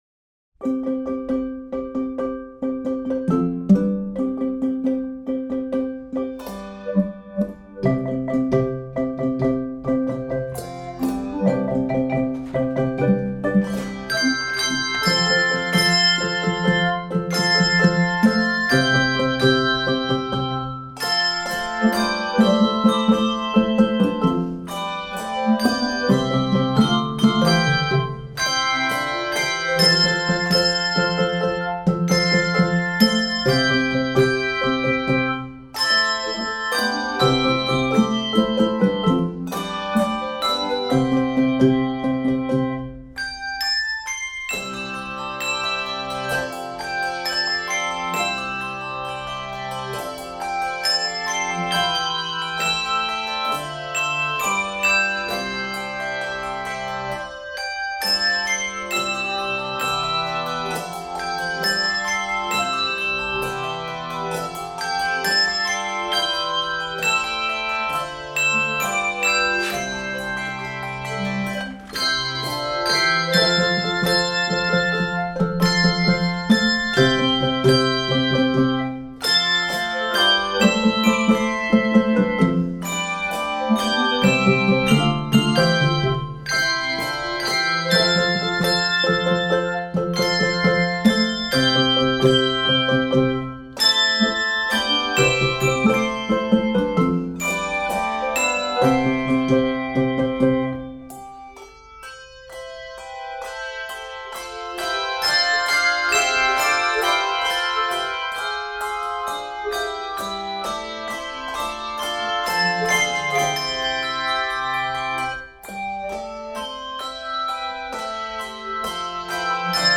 Voicing: 3-5 Octave Handbells